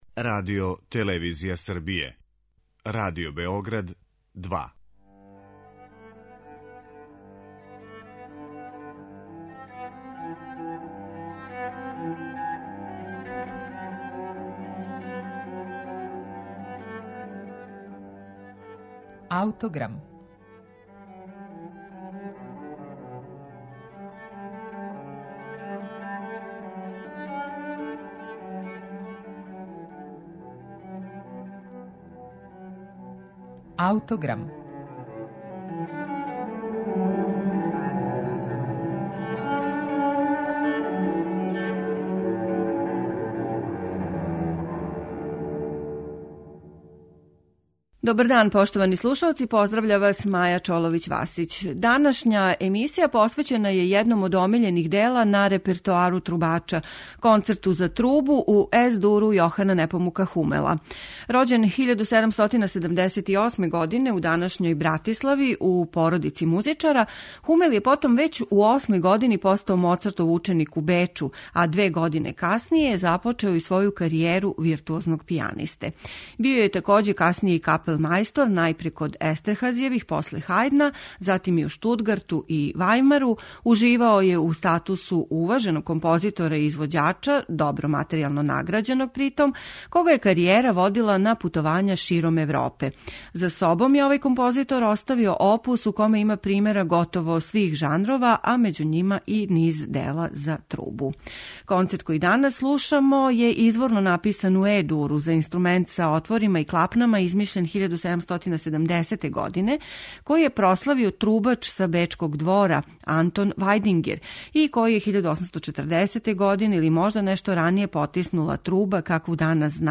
Концерт за трубу